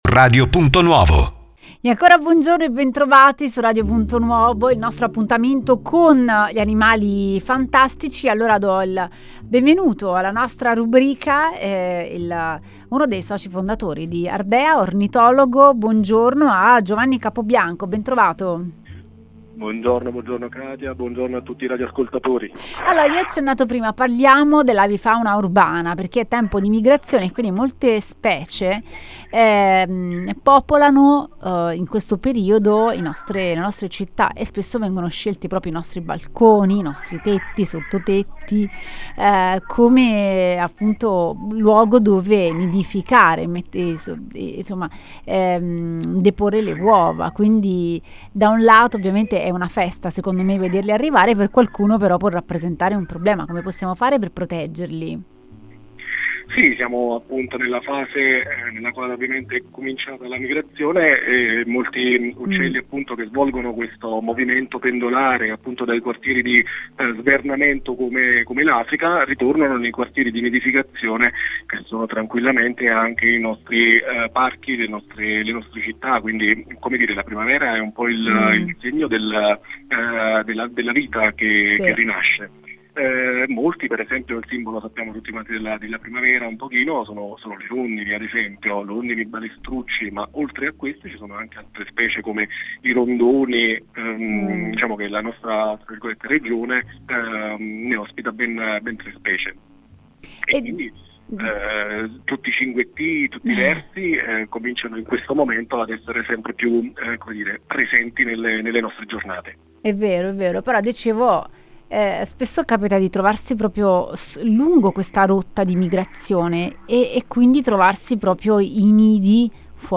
Ne abbiamo parlato ad Animali Fantastici con l’ornitologo